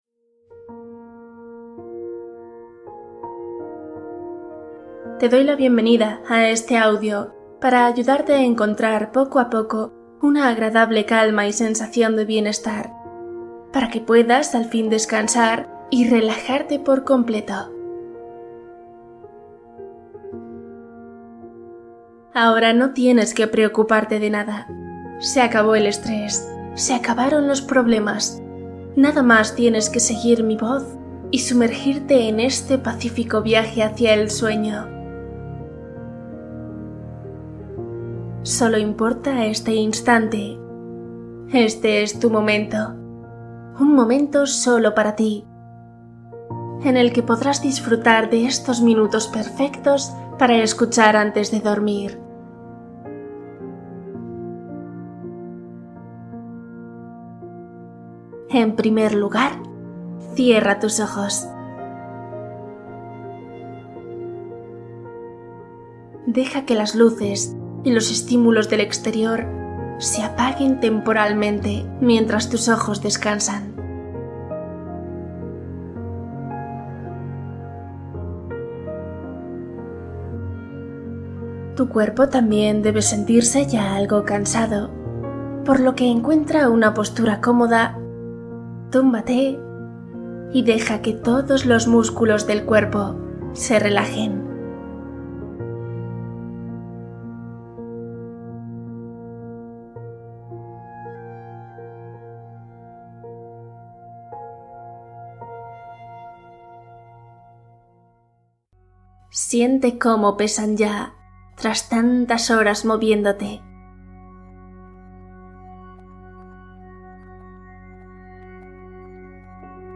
Sanación profunda mientras duermes: meditación para cuerpo y mente